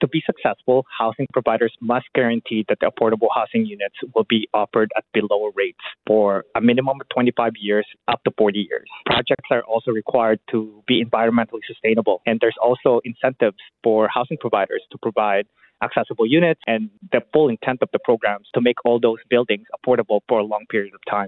affordable-housing-interview.mp3